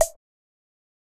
Perc 1.wav